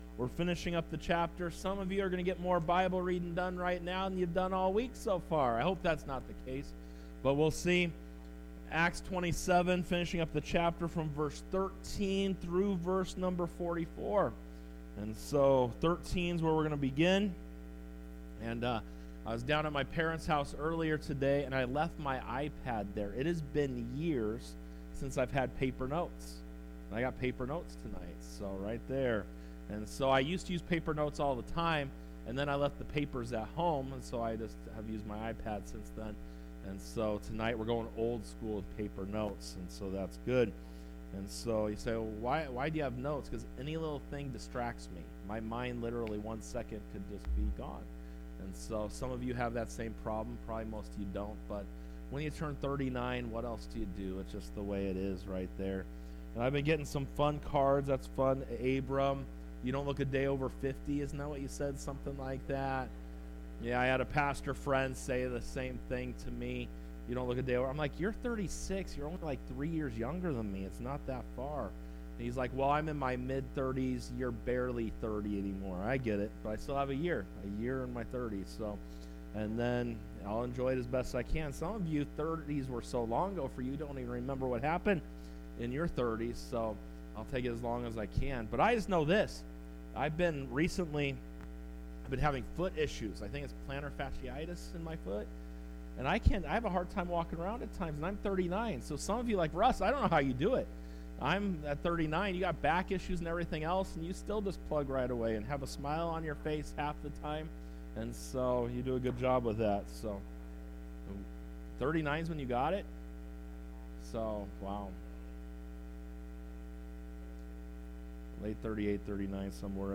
6.19.24 Wednesday Bible Study Our Shelter In The Storm